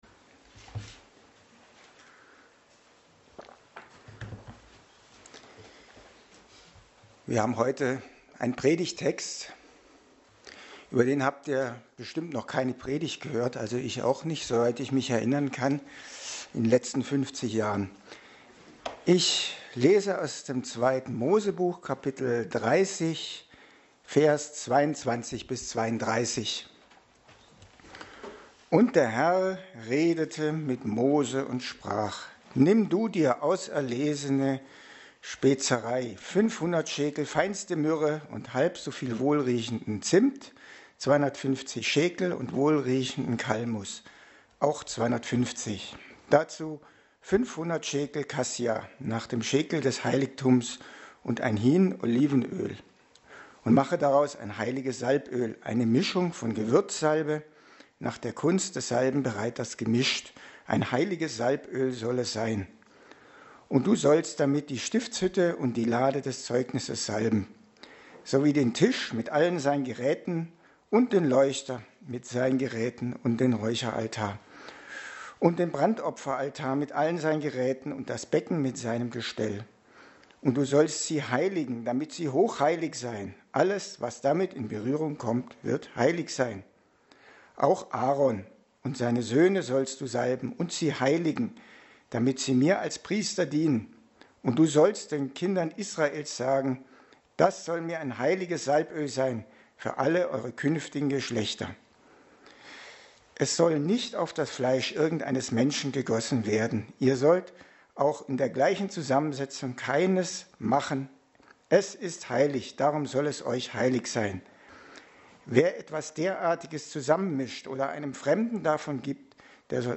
Mose 30,22-32 Dienstart: Predigt Themen: Heiliger Geist , Heiligkeit Gottes , Leben als Christ , Sünde « Dem heiligen Gott begegnen Karfreitag: Leid und/oder Freude?